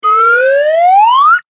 スライドホイッスル上昇2.mp3